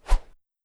Woosh 04.wav